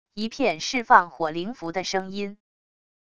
一片释放火灵符的声音wav音频